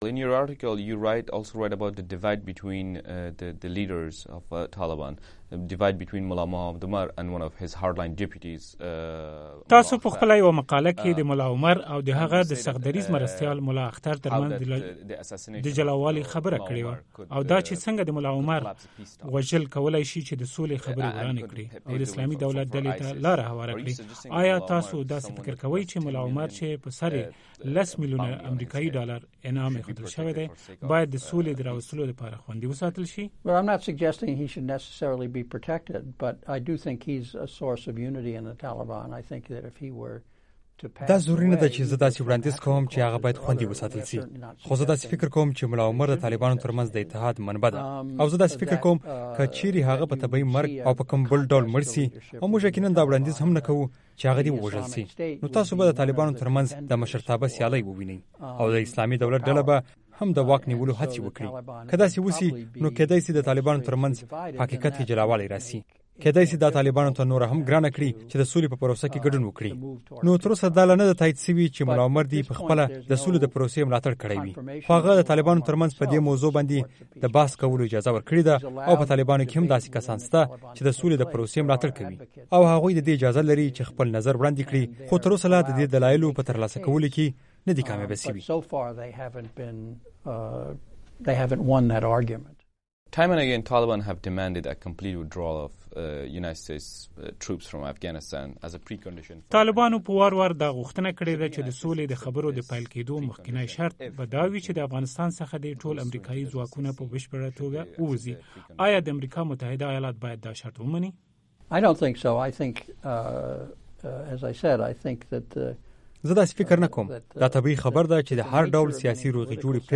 د پاکستان او افغانستان دپاره دامریکا پخواني استازي او د رینډکارپوریشن د څیړنیز مرکز کارپوه جیمز ډابنز د امریکا غږ اشنا تلویزون سره په مرکې کې له افغان حکومت سره د طالبانو د خبرو او پاکستان د ونډې په اړه خبرې وکړې.